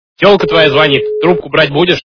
» Звуки » Смешные » Голос - Телка твоя звонит. трубку брать будешь
При прослушивании Голос - Телка твоя звонит. трубку брать будешь качество понижено и присутствуют гудки.